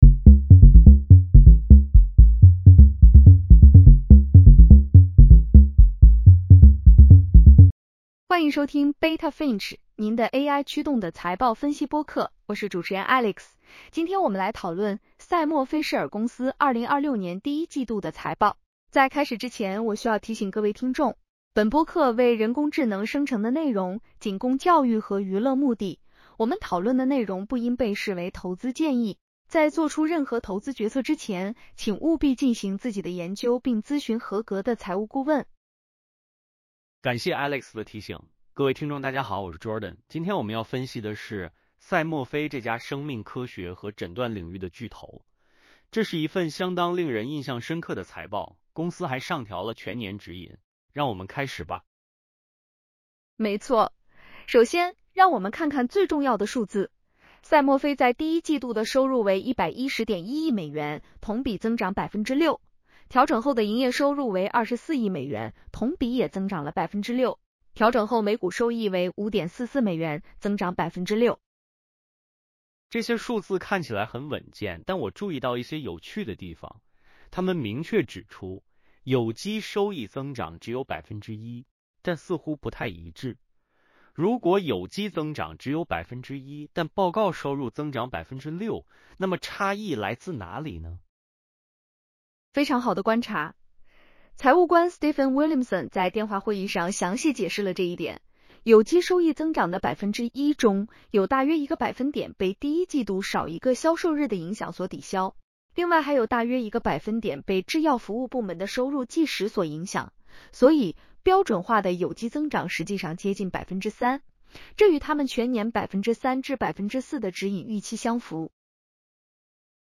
在开始之前，我需要提醒各位听众：本播客为人工智能生成的内容，仅供教育和娱乐目的。